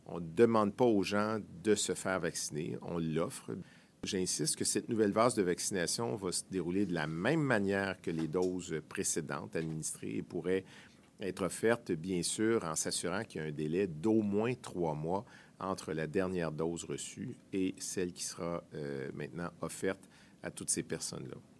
C’est ce qu’il a affirmé lors d’un point de presse ce mercredi le 23 mars.